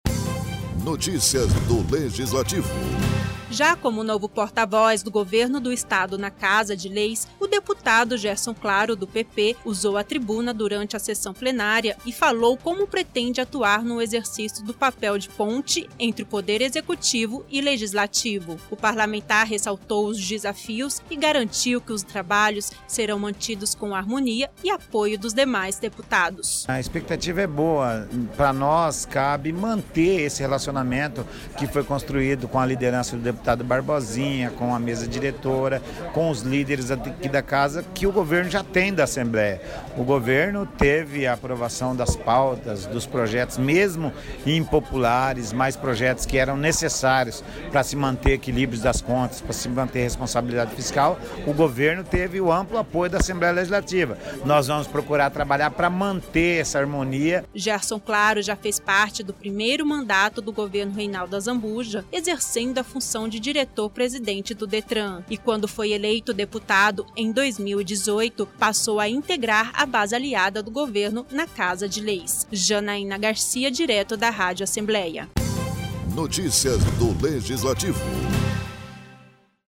Como novo porta voz do governo do estado na Casa de Leis, o deputado Gerson Claro do PP, usou a tribuna durante a sessão plenária para declarar como pretende atuar no exercício do papel de ponte entre o Poder Executivo e Legislativo.